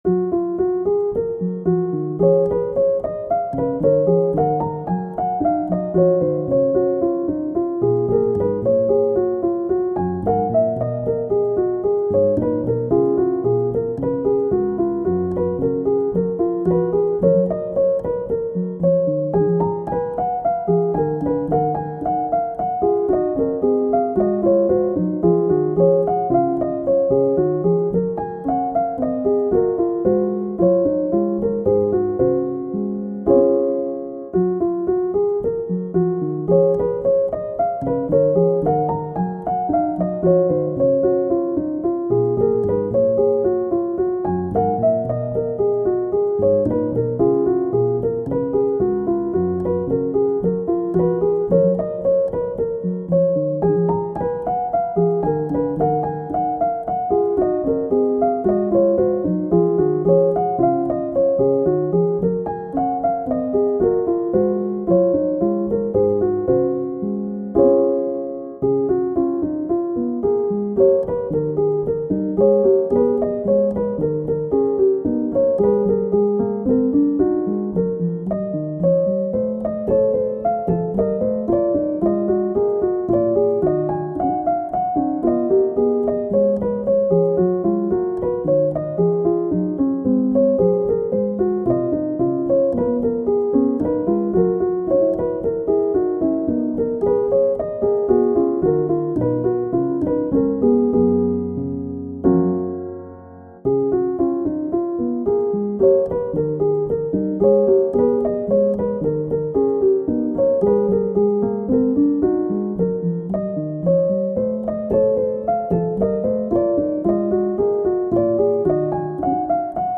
Goldberg Variation - Piano Music, Solo Keyboard - Young Composers Music Forum